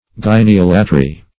Gyneolatry \Gyn`e*ol"a*try\ (j[i^]n`[-e]*[o^]l"[.a]*tr[y^]), n.